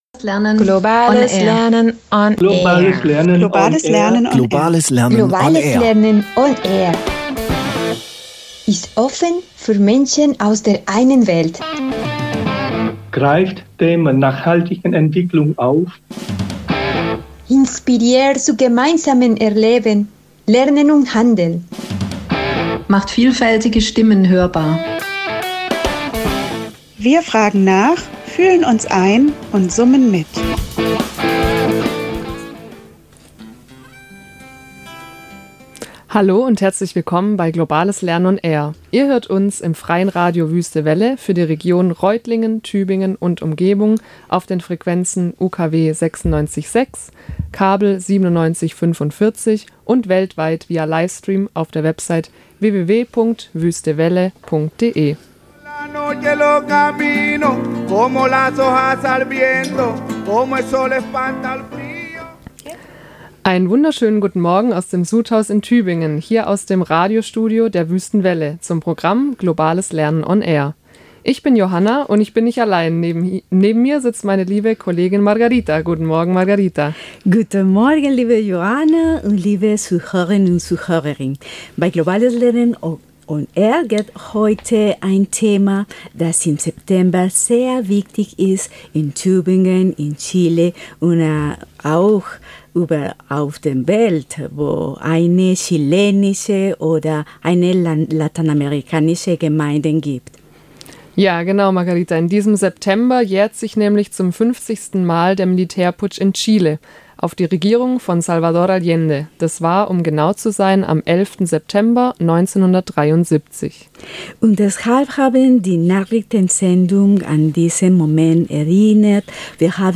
Dafür haben wir mit drei Chilenen gesprochen, die ihre persönliche Perspektive und Erinnerung auf die Geschichte mit uns teilen.